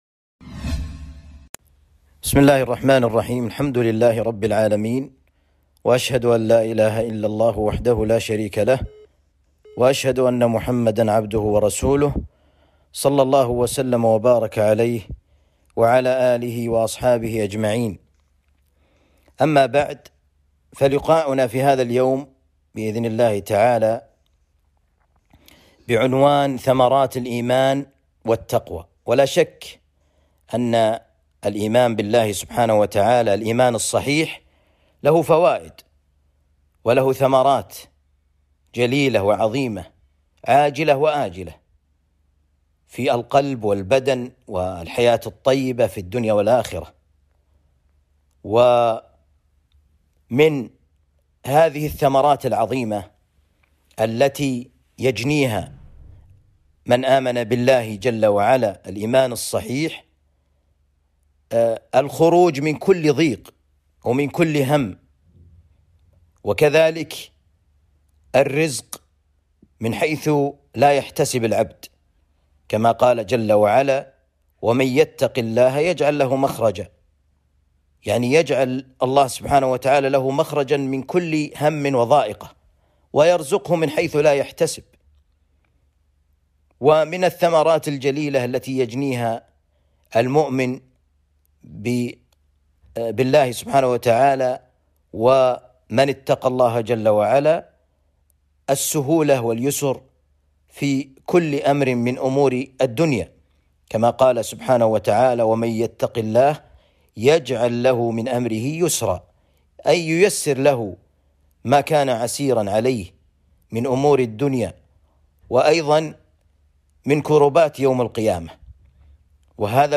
محاضرة بعنوان ثمرات الإيمان والتقوى